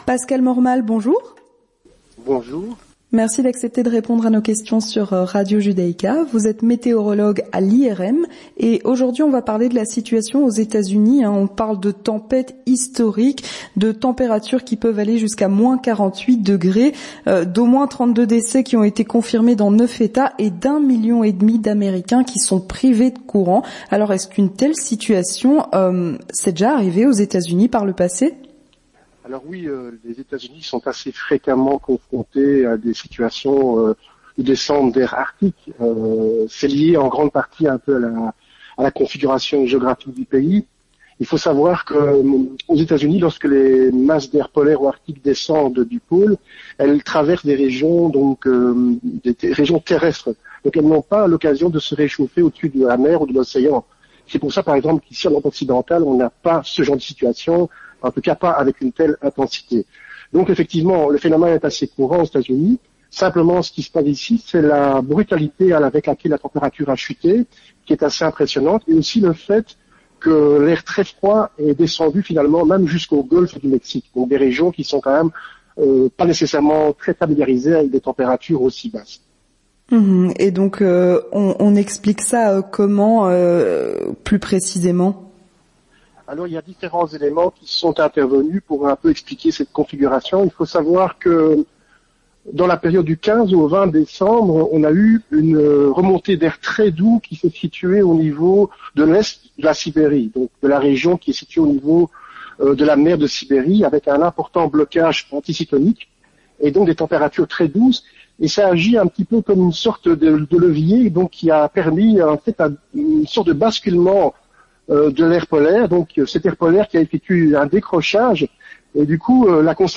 L'Entretien du Grand Journal